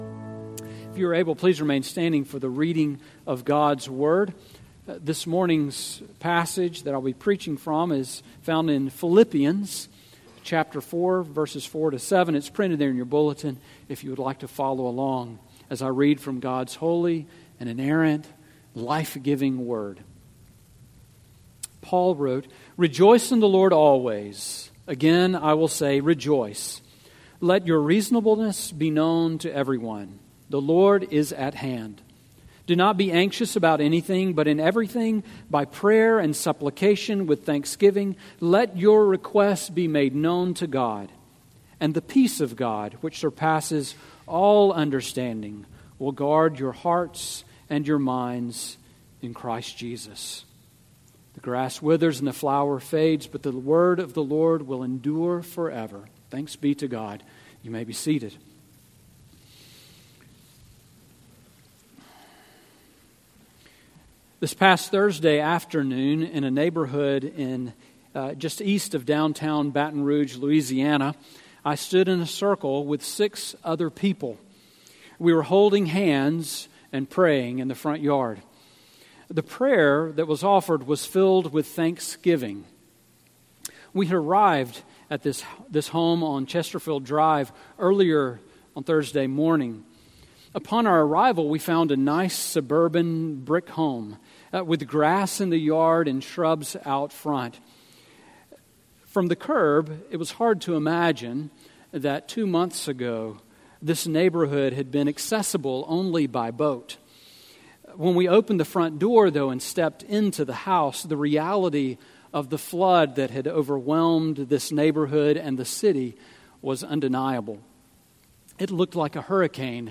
Sermon Audio from Sunday